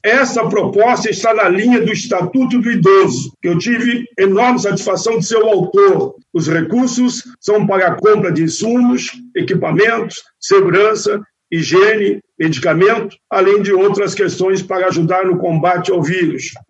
O presidente da Comissão de Direitos Humanos, senador Paulo Paim, do PT do Rio Grande do Sul, autor da proposta que destina 160 milhões de reais para proteção dos idosos e enfrentamento ao coronavírus, comemora a aprovação da medida pelo Senado Federal.